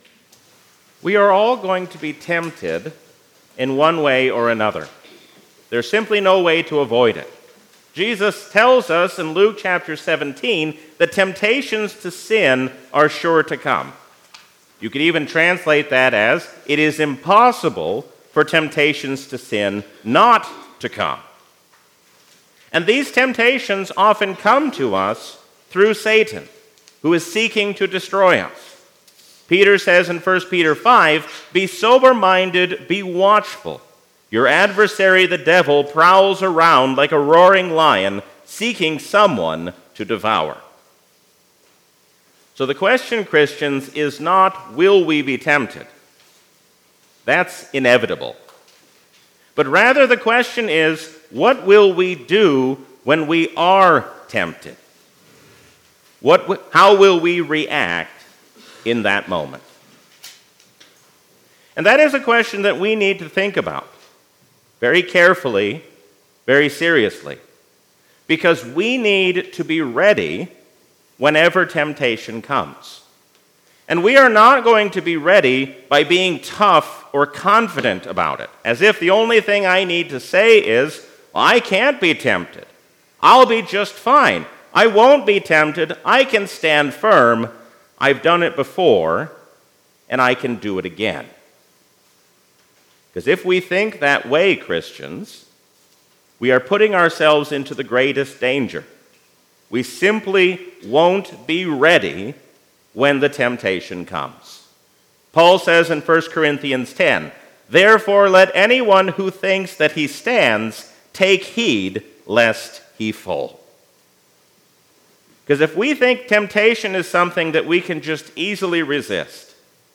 A sermon from the season "Lent 2023." Jesus teaches us what it means to forgive those who have hurt us, even as He hangs on the cross.